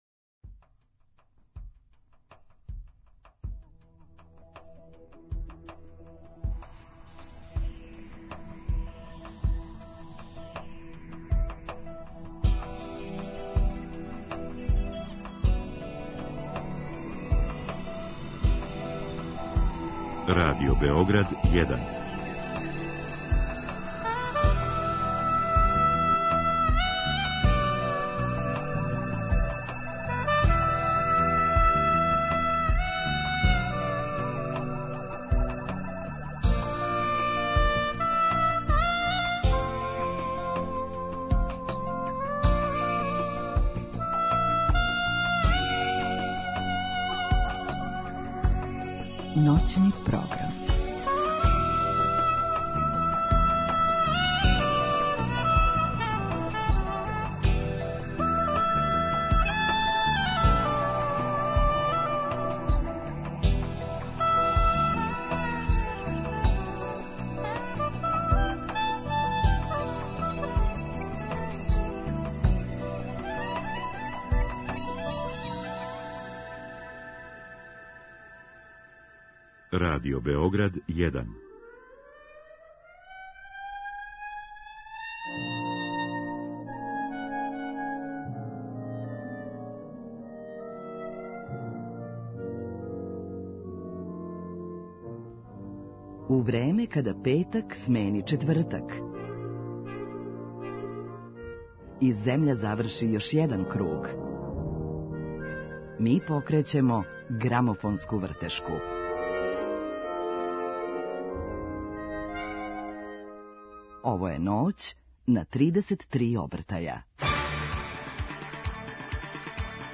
Гост Миодраг Бата Костић, музичар, гитариста, композитор и продуцент.